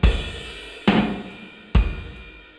Você ainda pode ver as três batidas distintas e, se ouvir o sinal de saída (drum_seg_output.wav), ouvirá o efeito do sistema aplicado nesta entrada (neste caso, as alto frequências foram atenuadas -- filtro passa-baixas):
Não há diferença significativa nos gráficos, exceto que as amostras na "cauda" após a primeira batida são visivelmente menores na saída; no entanto, você ouvirá claramente a diferença se ouvir a entrada e a saída.
drum_seg_output.wav